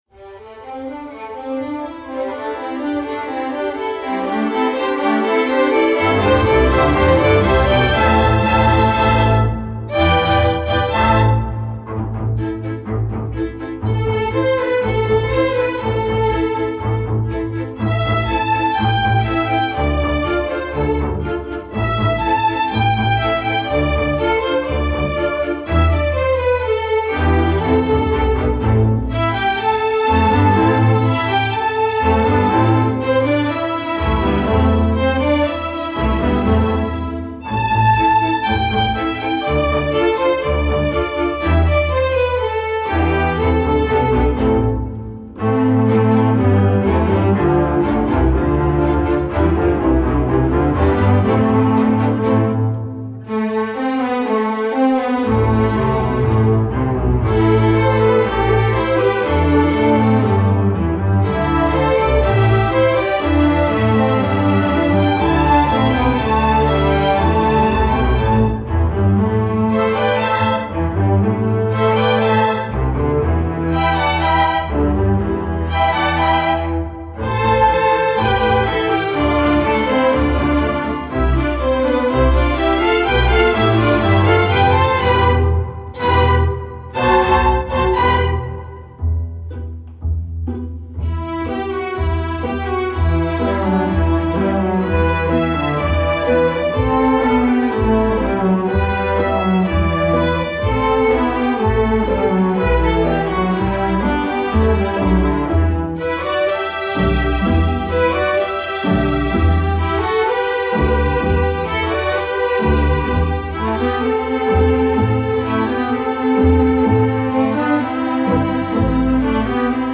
Voicing: with opt. 3rd Violin/Viola TC 3